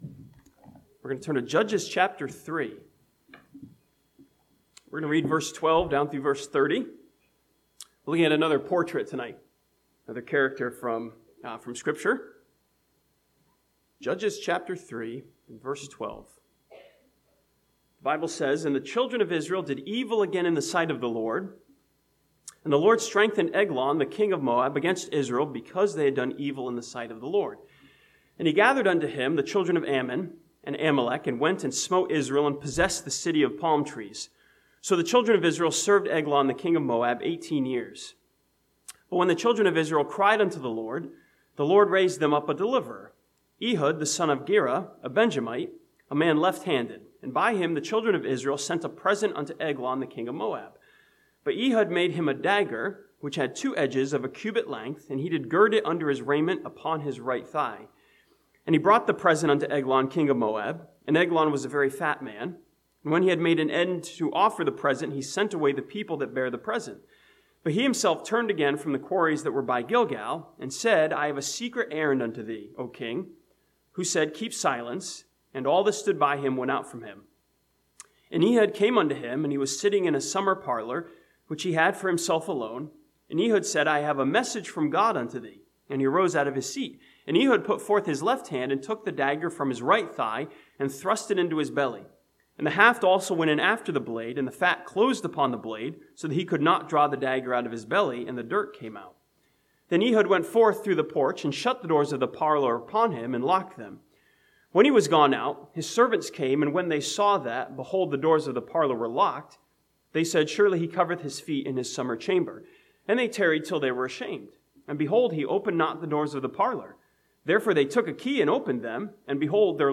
This sermon from Judges chapter 3 studies Ehud as the portrait of a fighter who did what needed to be done.